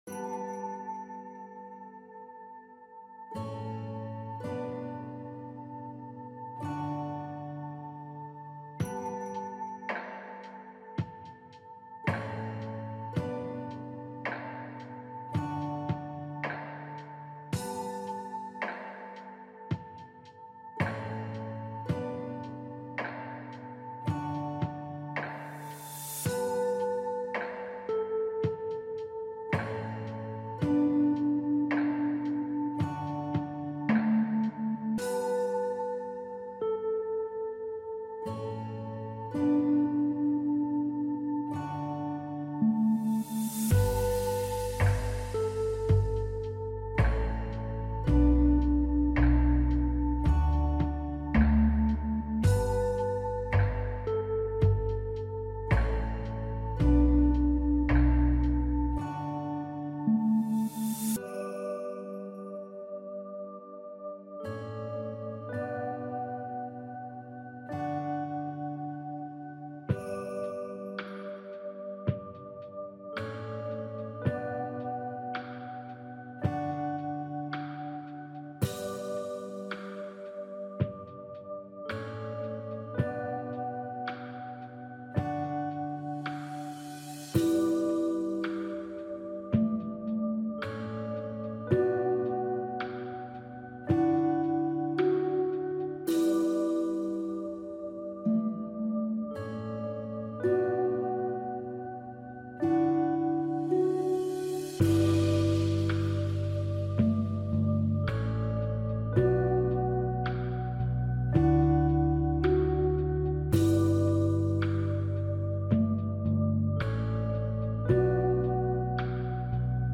a calm blend of ambient lo-fi and soft textures.
Just you, the clouds, and the hum of stillness.